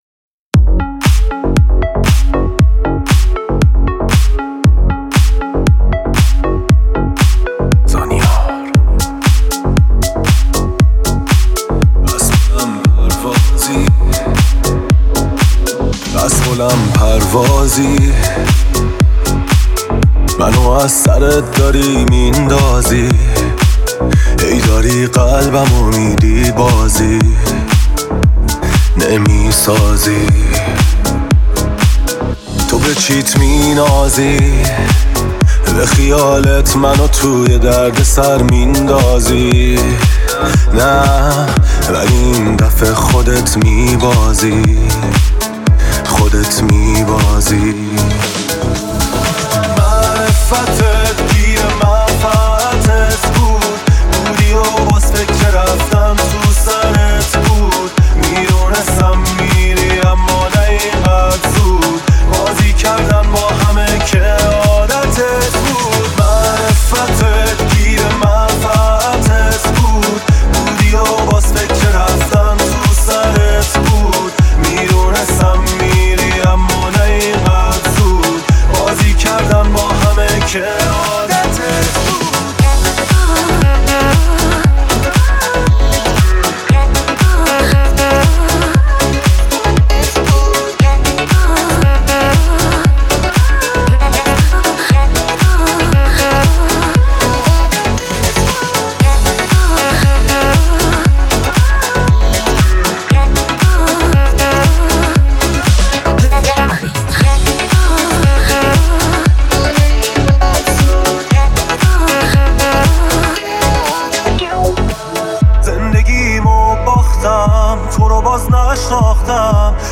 موسیقی